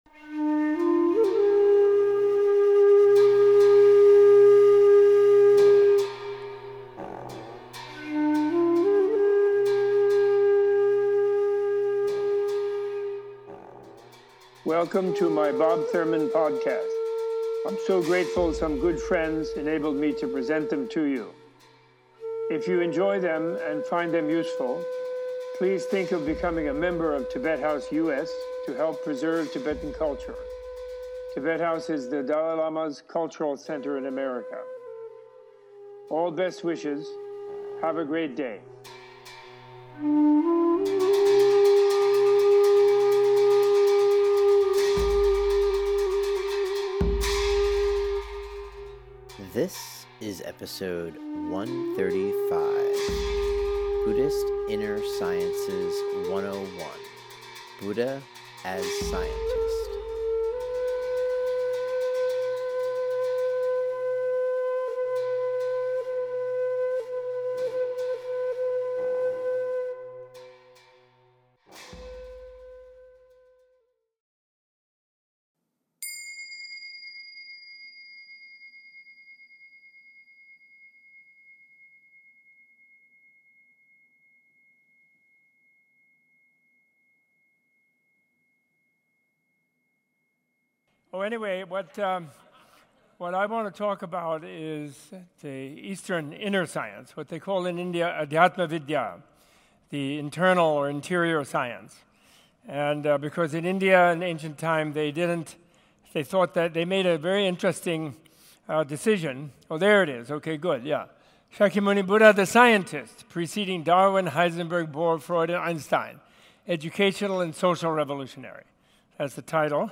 Includes a guided selflessness meditation, an explanation of the concept of voidness and an in depth introduction to the Buddhist Inner Sciences.